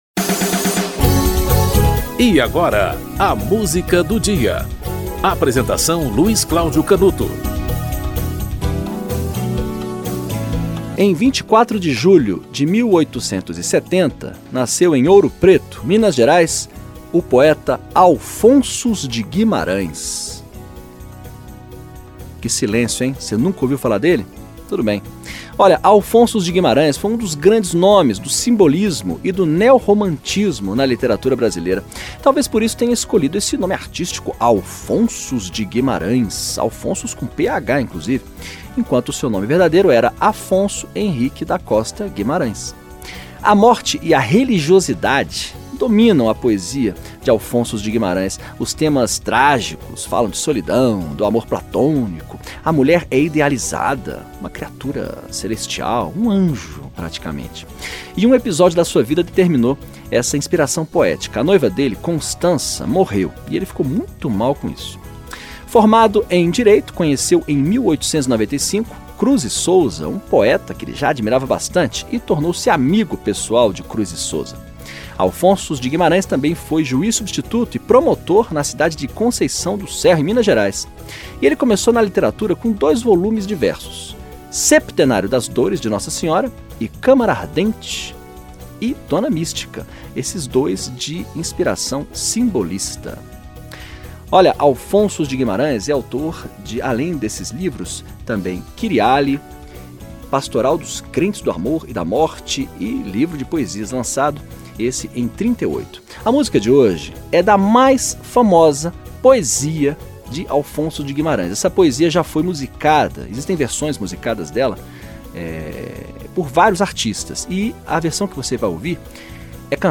Inezita Barroso - Ismália (Alphonsus de Guimarães)